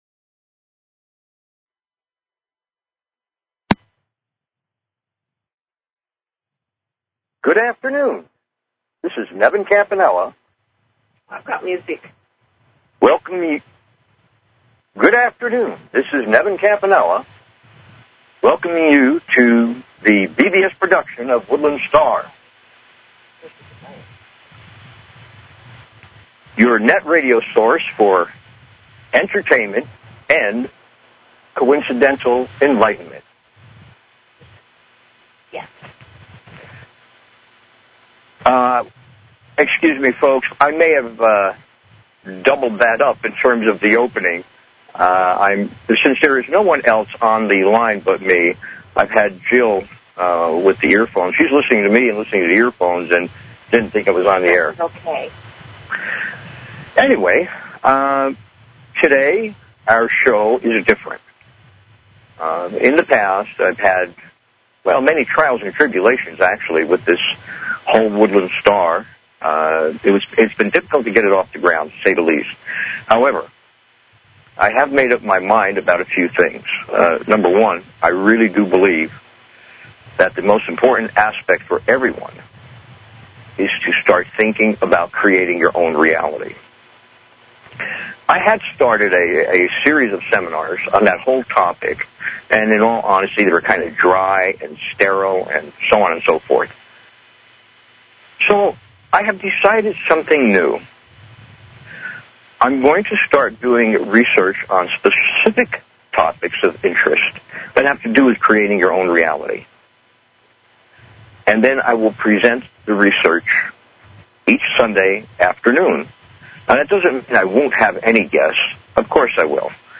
Talk Show Episode, Audio Podcast, Woodland_Stars_Radio and Courtesy of BBS Radio on , show guests , about , categorized as